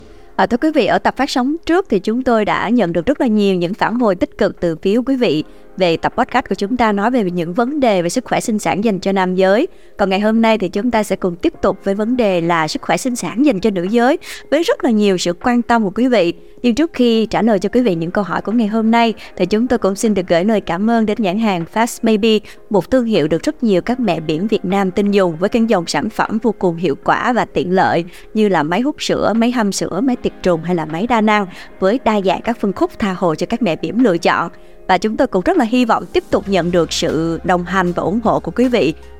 Vietnamese_Female_Sourse.wav